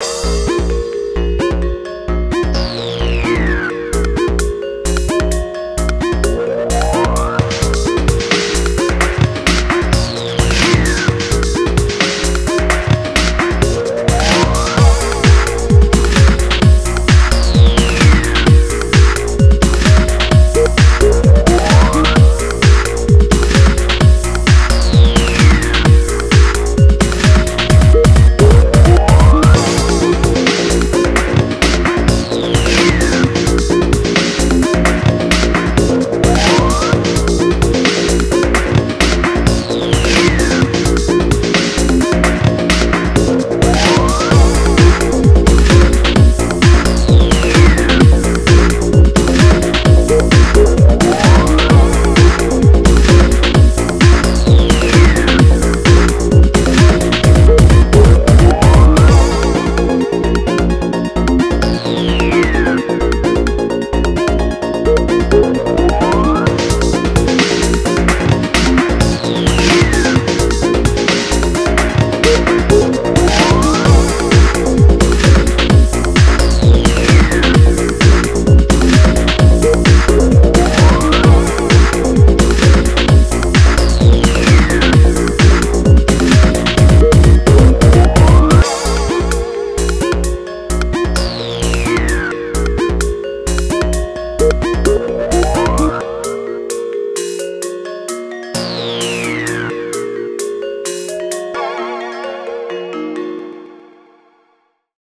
BPM130
Comments[GAMELAN TECHNO]